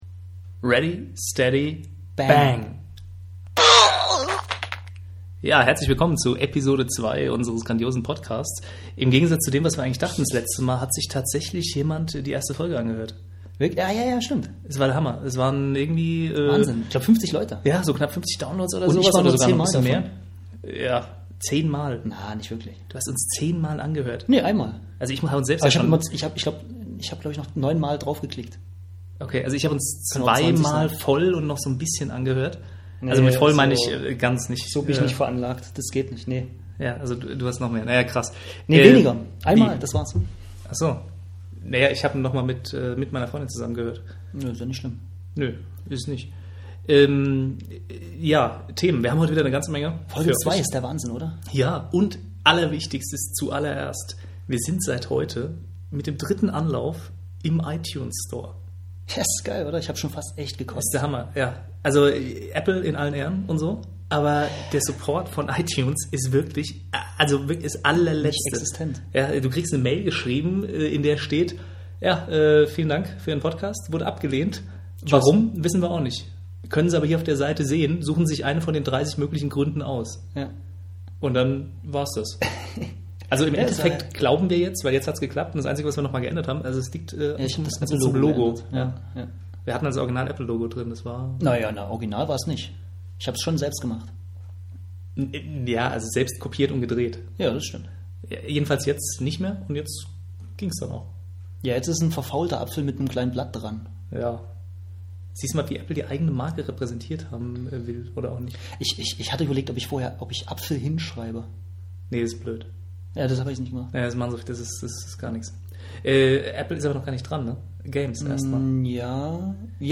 Episode 2: Ready Steady Bang und epischer Mikrofon-Fail
In dem Fall unser Mikrofon. Während sich Amazon über die Rücksendung eines Samson Meteor Mic freuen darf (wir können es nicht wirklich empfehlen), könnt ihr euch trotzdem über etwas mehr als eine halbe Stunde Philosophieren über Spiele freuen.
Genug jetzt mit schlechter Technik.